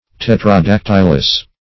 Search Result for " tetradactylous" : The Collaborative International Dictionary of English v.0.48: Tetradactylous \Tet`ra*dac"tyl*ous\, a. [Gr.